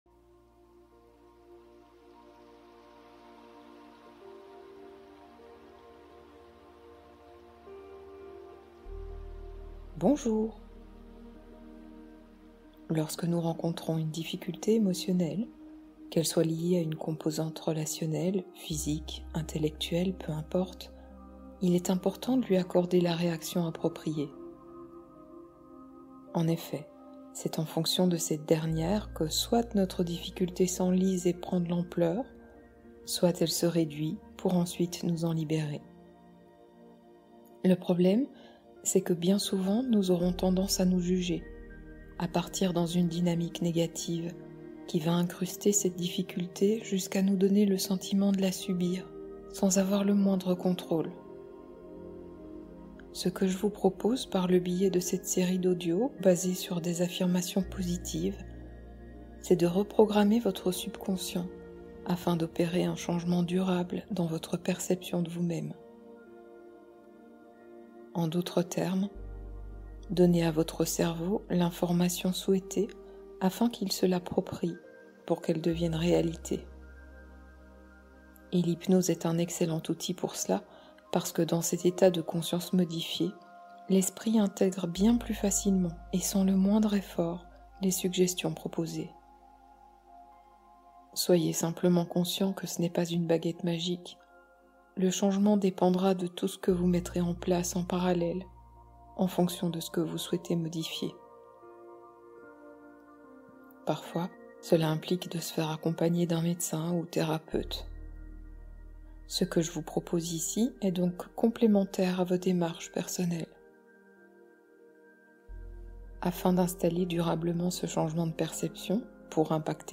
Envol vers le sommeil : 1h de détente guidée